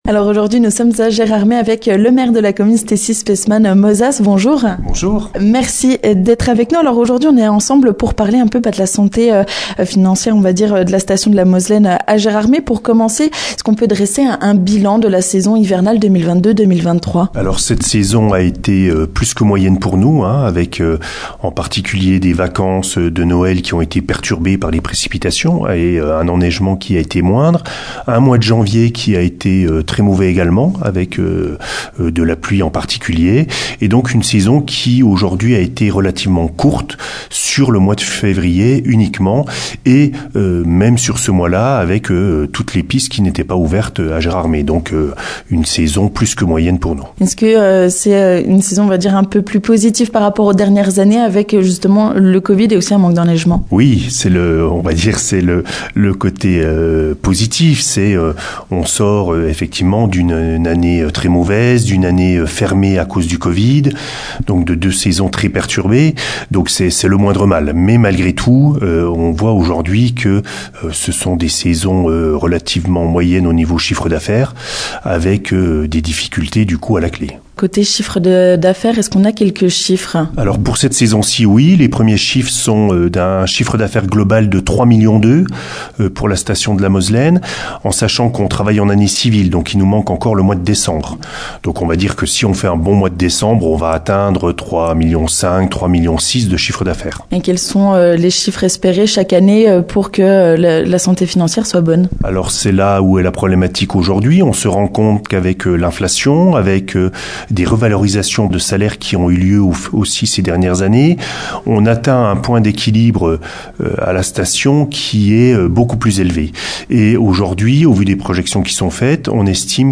On en parle avec le Maire de Gérardmer, Stessy Speissmann-Mozas !